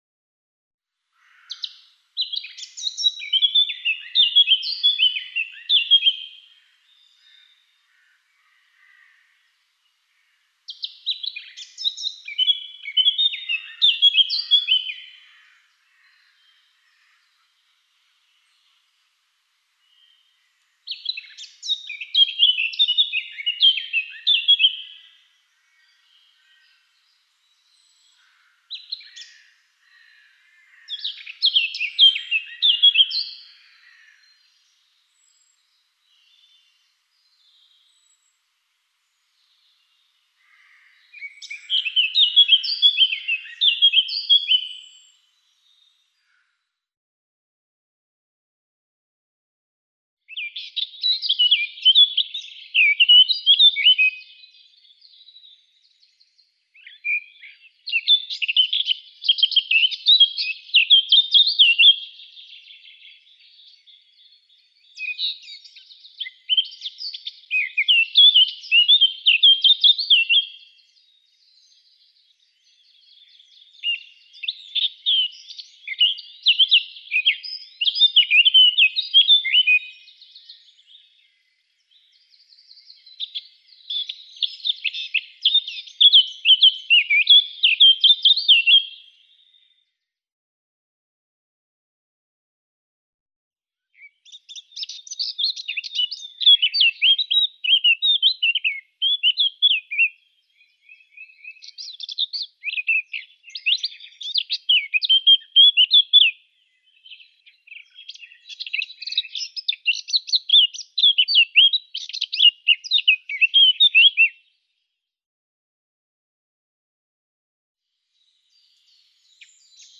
Sylvia_atricapilla_Track_43.mp3